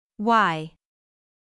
/ai/は、日本語でいうと「アーェ」のような音になります。
強く長めに日本語の「ア」を発音しながら徐々に顎を閉じて弱く短い「ェ」に移行していくようなイメージです。